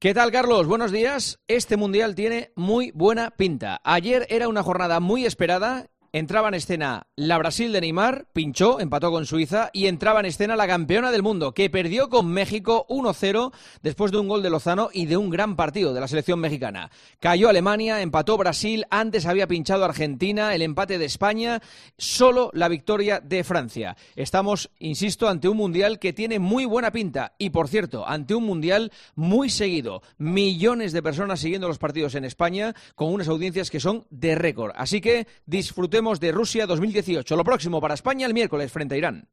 AUDIO: Escucha el análisis de lo que llevamos de Mundial de fútbol de Rusia del director de 'El Partidazo' de COPE